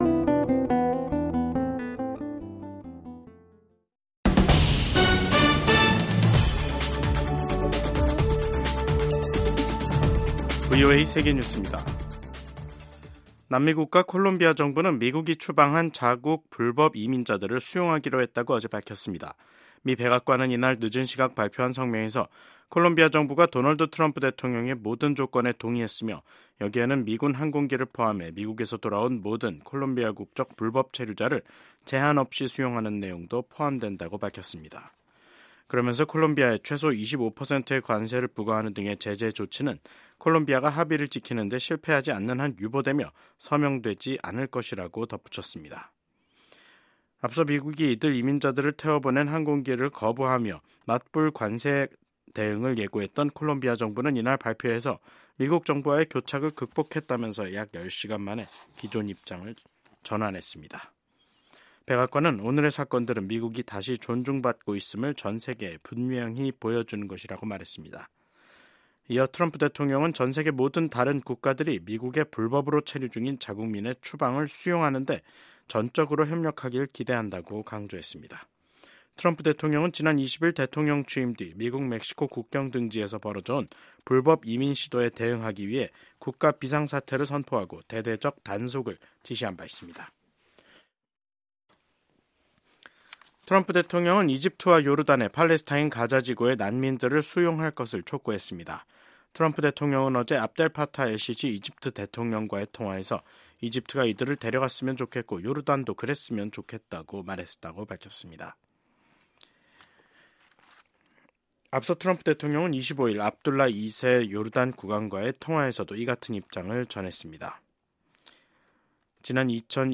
VOA 한국어 간판 뉴스 프로그램 '뉴스 투데이', 2025년 1월 27일 2부 방송입니다. 북한은 김정은 국무위원장이 참관한 가운데 서해상으로 전략순항미사일을 시험 발사했습니다. 미국 하원에서 미한 동맹의 중요성을 재확인하는 결의안이 발의됐습니다. 영국 국방부는 러시아에 파병된 북한군의 3분의 1 이상이 사망하거나 부상한 것으로 추정했습니다.